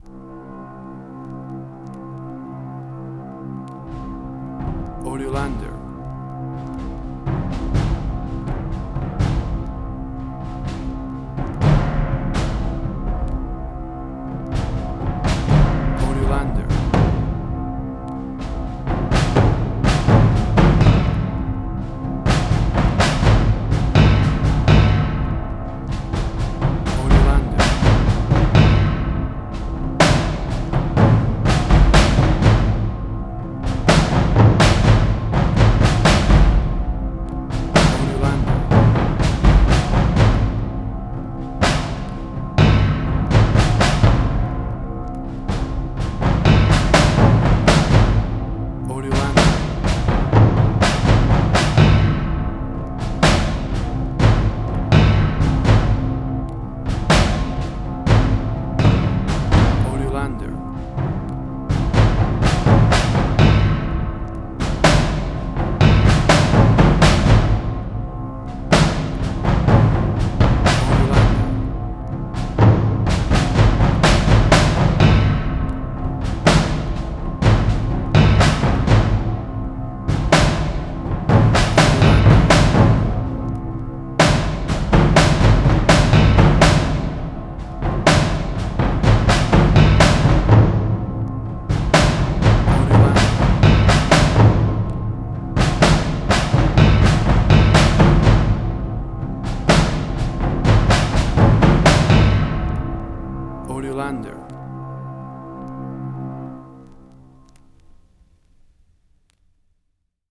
Modern Film Noir.
Tempo (BPM): 62